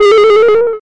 酸味 " 高低音哔哔声
描述：酸模拟模型的声音来自非基于样本的键盘/软合成器。（不是来自预设）。改变和扭曲，过滤和反弹。
标签： 类似物 贝斯 键盘乐器 声音 合成器 音调
声道立体声